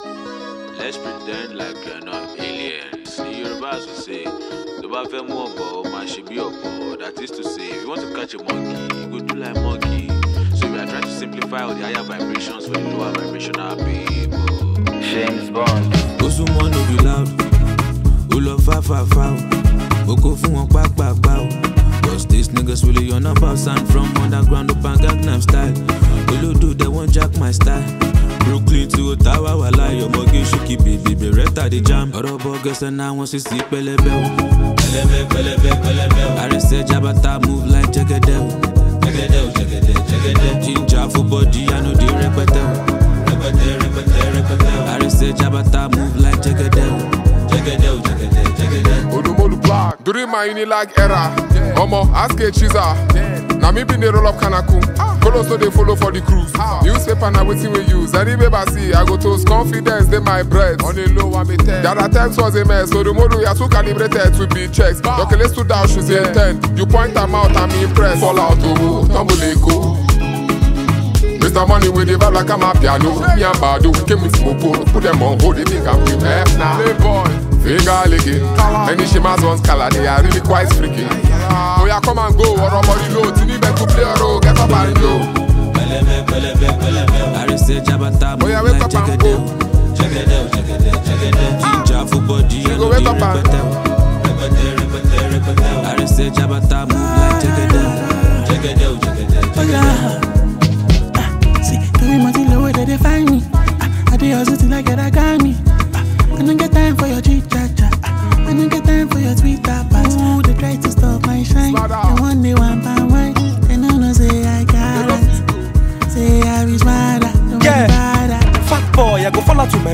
Afrobeats Released